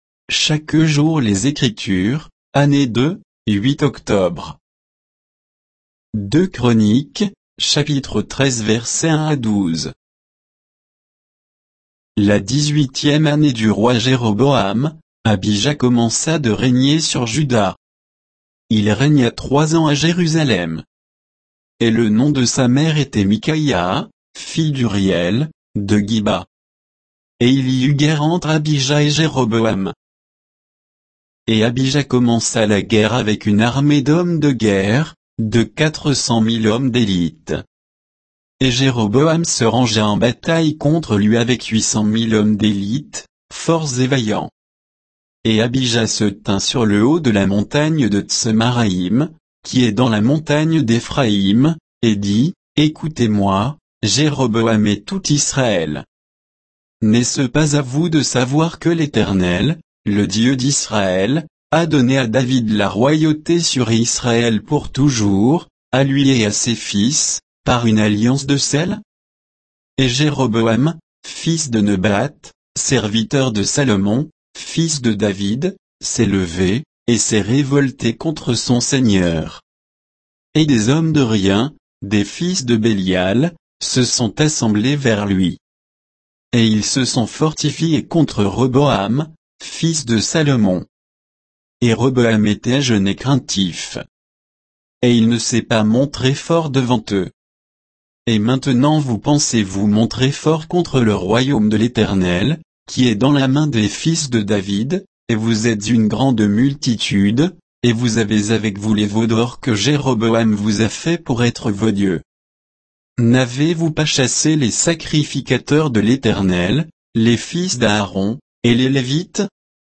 Méditation quoditienne de Chaque jour les Écritures sur 2 Chroniques 13